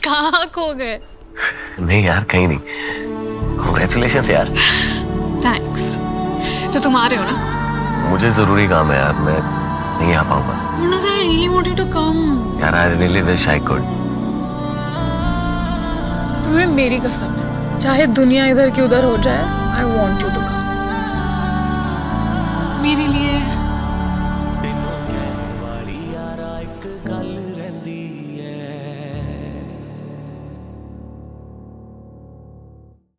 Category Punjabi